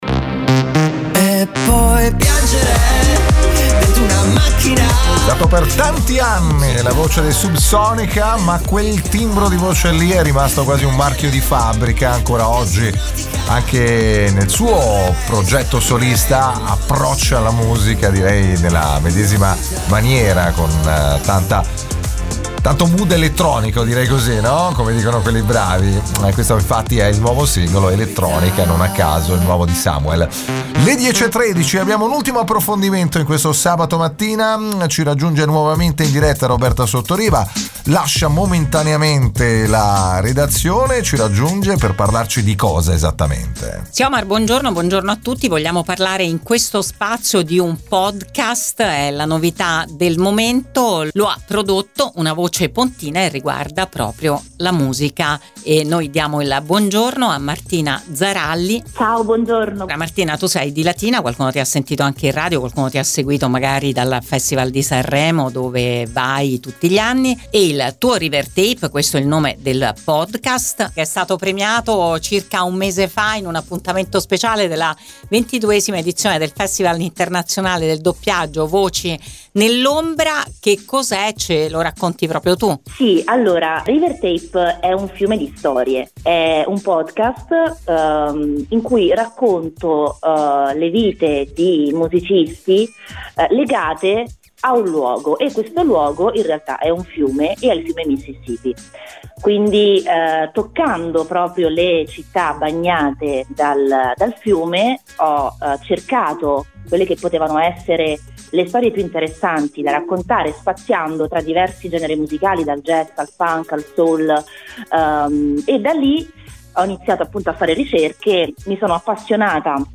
Ne abbiamo parlato con lei su Radio Immagine